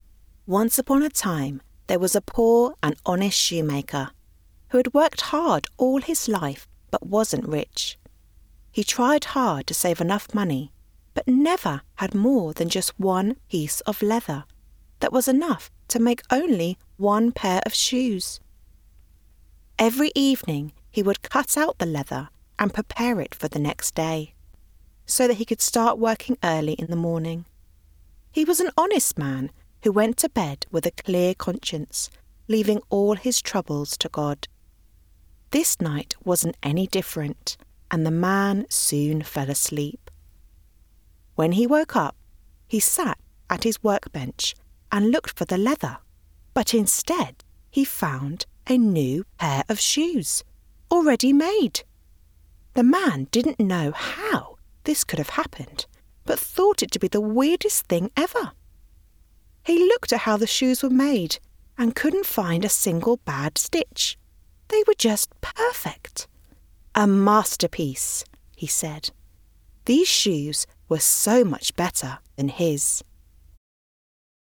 Immerse your audience in the world of your story with Crown Stag's captivating audiobook narration. Our voice actors deliver emotional depth and distinctive character voices, perfect for transforming any book into a memorable listening experience.
pg+Audio+Book.mp3